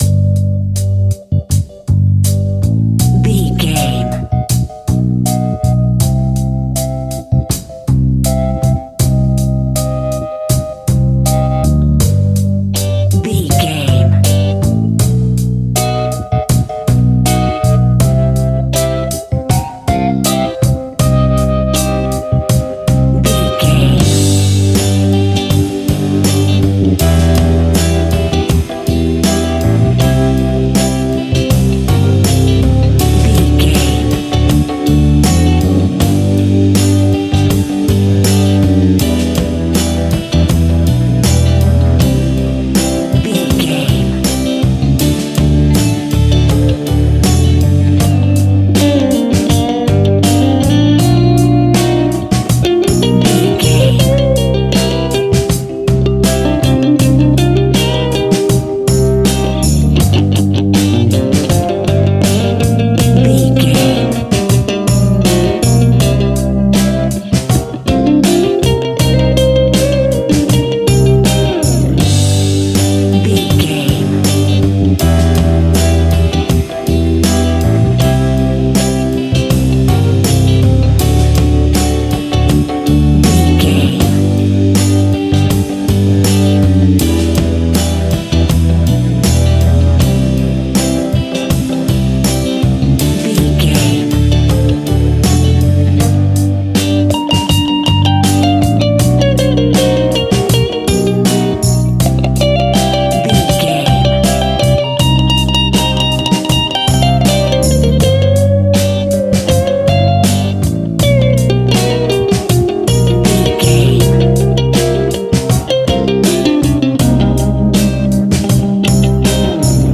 Ionian/Major
cool
funky
uplifting
bass guitar
electric guitar
organ
percussion
drums
saxophone
groovy